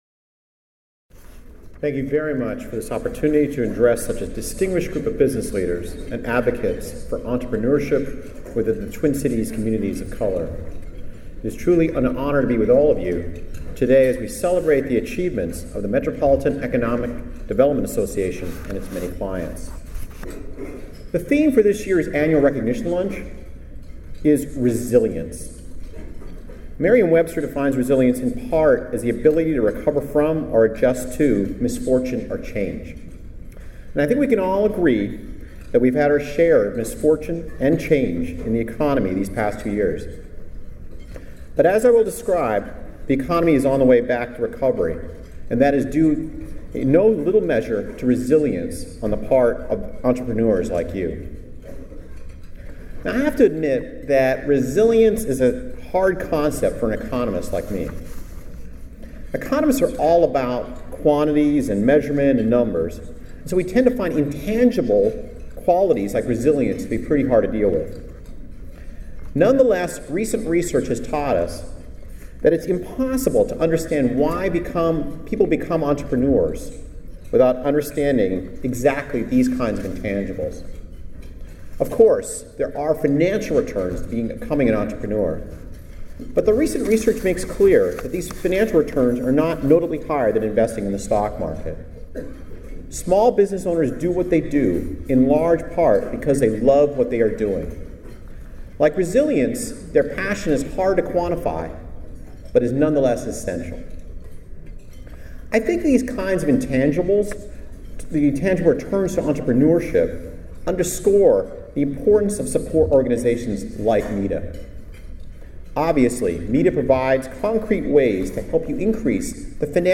Speech (audio) Thank you very much for this opportunity to address such a distinguished group of business leaders and advocates for entrepreneurship within the Twin Cities’ communities of color.